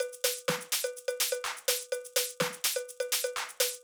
Drumloop 125bpm 03-B.wav